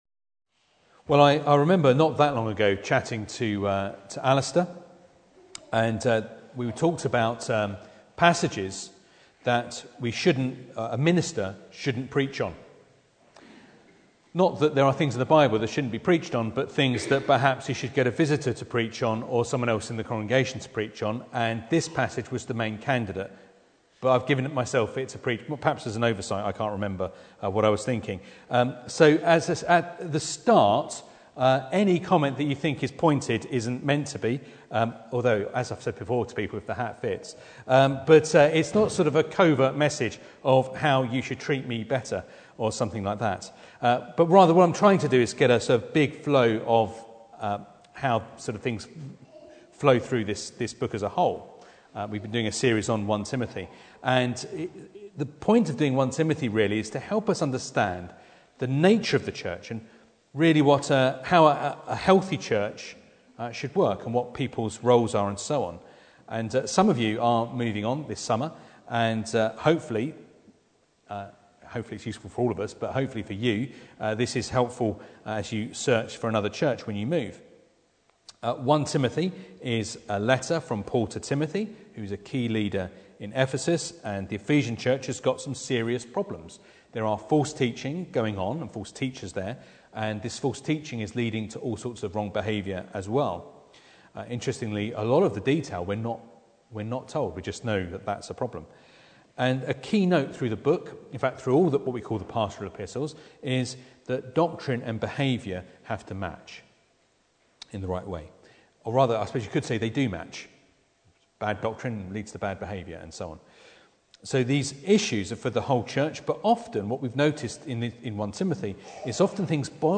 Passage: 1 Timothy 5:17-25 Service Type: Sunday Morning